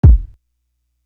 Stuck To You Kick.wav